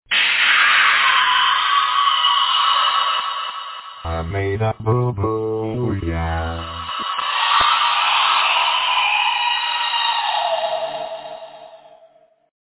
it goes "owwwwww"